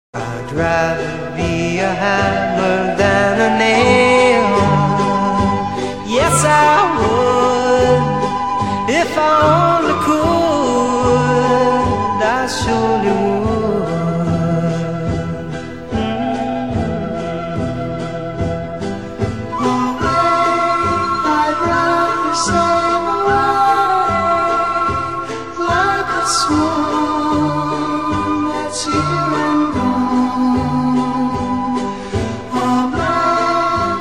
벨소리